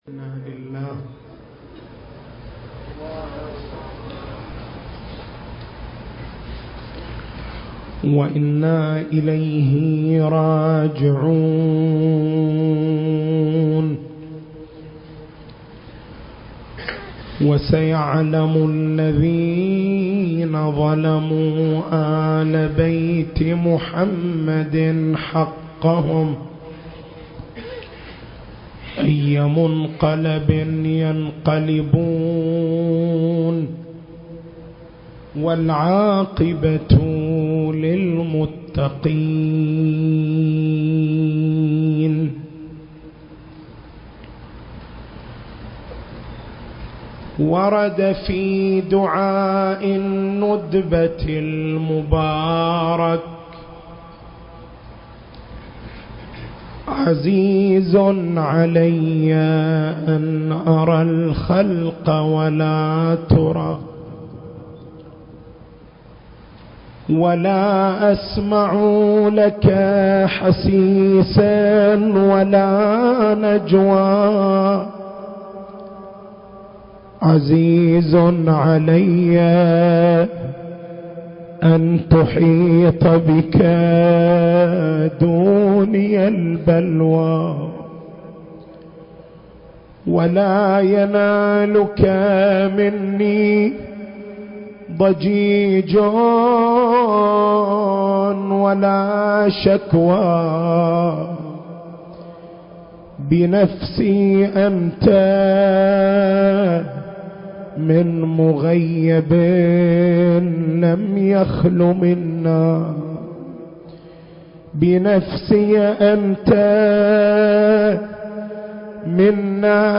المكان: حسينية الملا عبد الله الزين - القطيف التاريخ: 2019